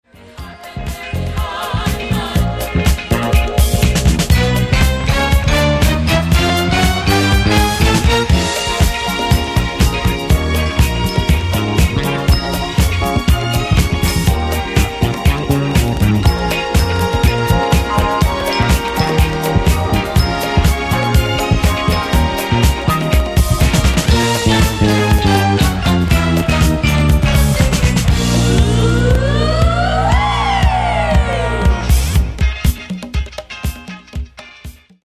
Genere:   Disco | Funk | Soul